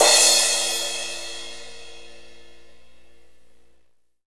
CYM A C CR0M.wav